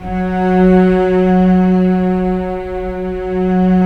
Index of /90_sSampleCDs/Roland LCDP13 String Sections/STR_Vcs I/STR_Vcs1 Sym Slo